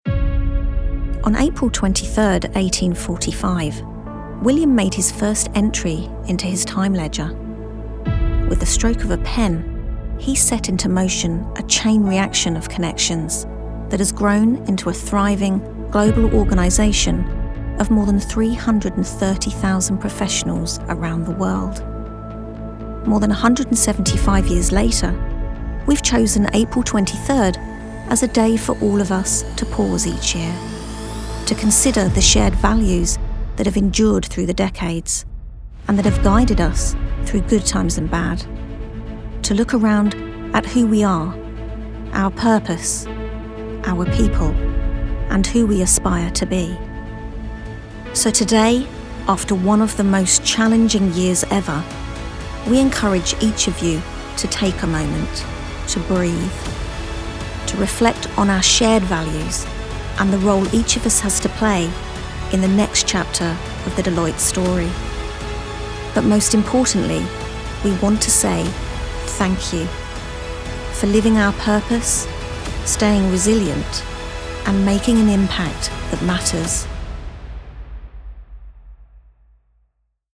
Female
Yng Adult (18-29), Adult (30-50)
Her calm, friendly, and articulate tone is easily understood by global audiences, making her ideal for corporate narration, e-learning, and explainer content.
Corporate
Corporate Video
Words that describe my voice are Calm, Authoritative, Conversational.